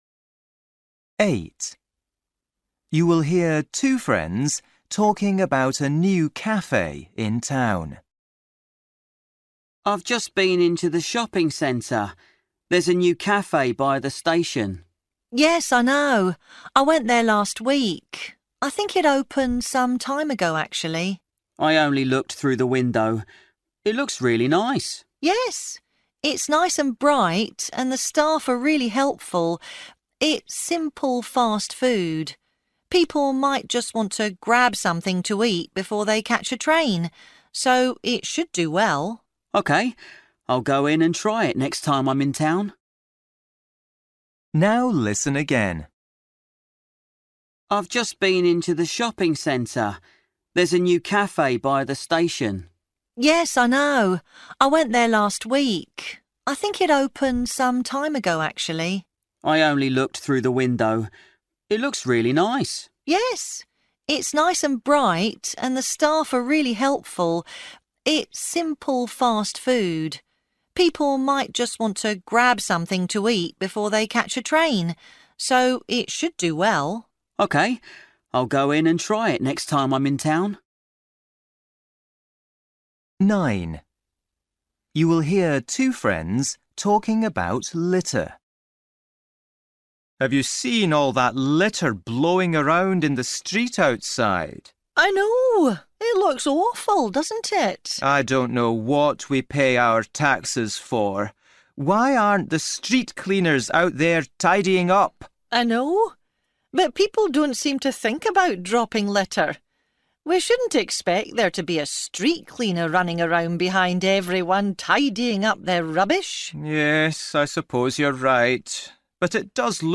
Listening: everyday short conversations
8   You will hear two friends talking about a new cafe in town. What does the woman say about it?
10   You will hear a woman telling a friend about a new car. What does she say about it?